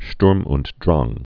(shtrm nt dräng)